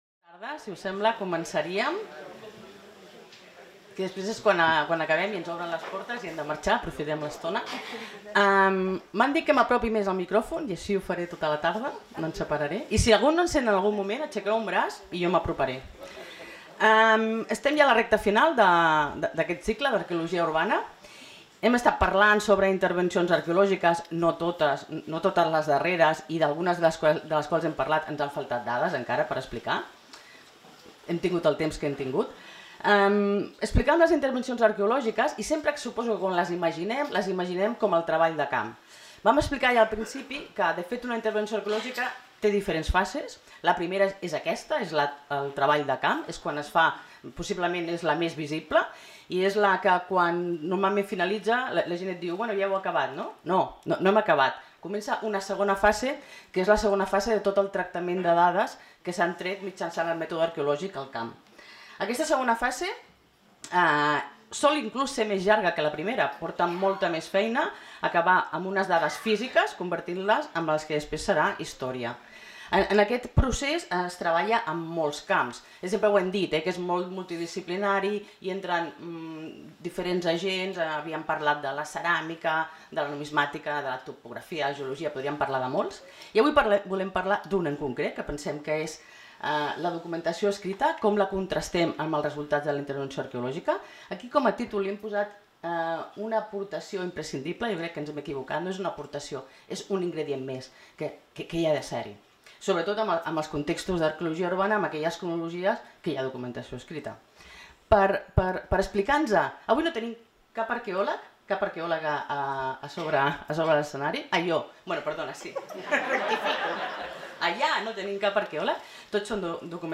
Conferència-xerrada